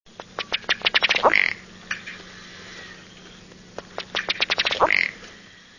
głosy innych sterniczek
sterniczka jamajska
oxyura jamaicensis  wav23 kb